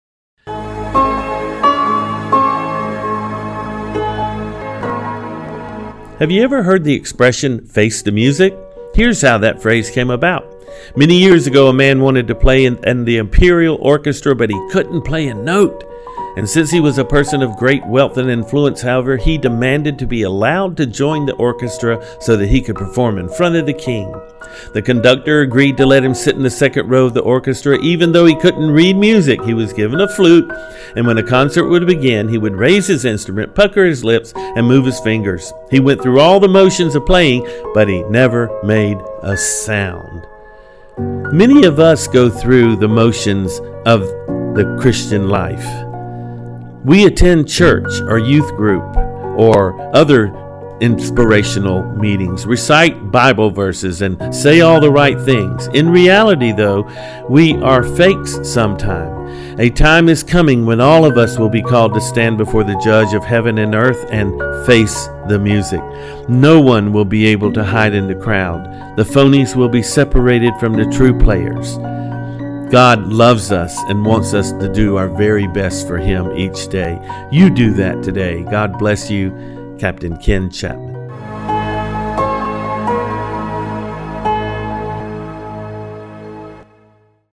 Daily Devotionals